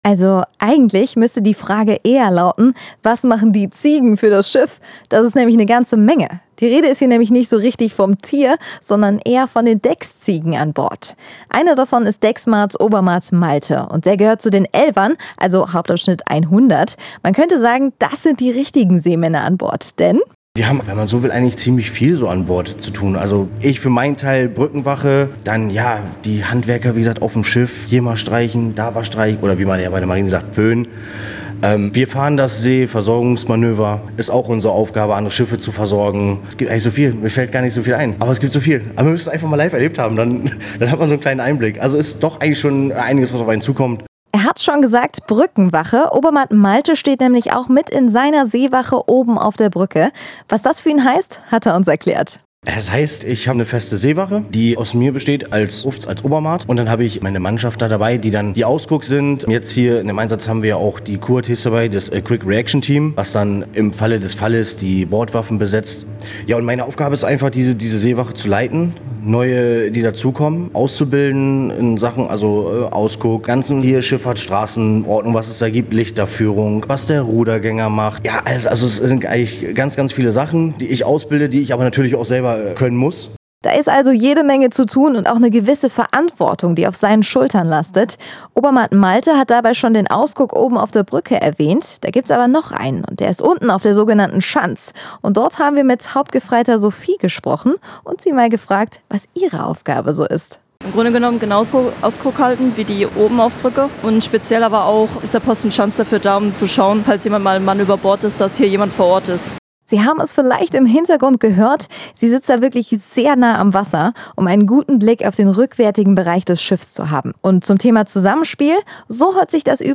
Deckziege Obermaat